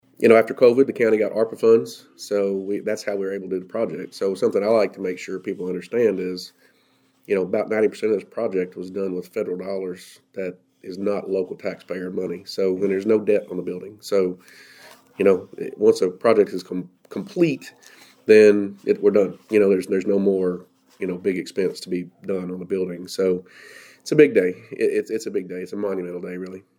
During an interview with WFMW’s News Edge, Sheriff Matt Sanderson said the new building marks a monumental moment for both the Sheriff’s Office and Hopkins County residents.